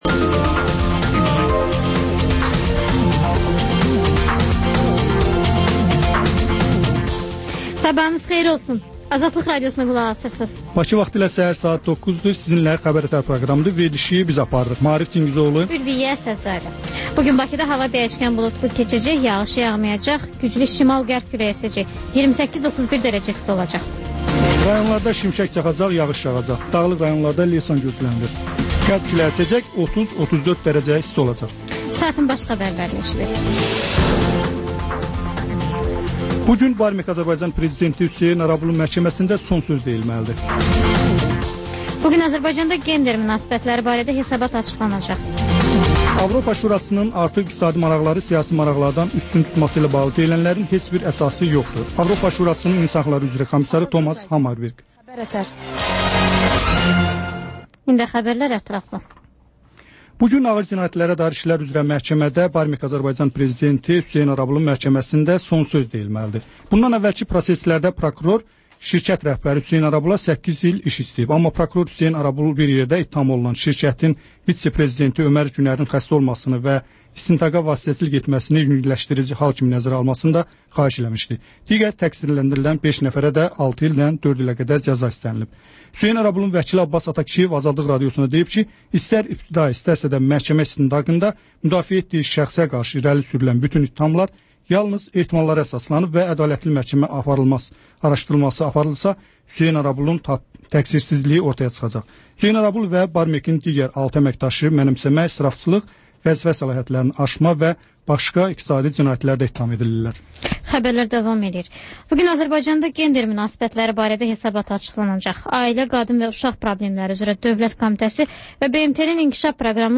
Xəbər-ətər: xəbərlər, müsahibələr, sonra PANORAMA verilişi: Həftənin aktual mövzusunun müzakirəsi